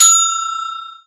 Bell3.ogg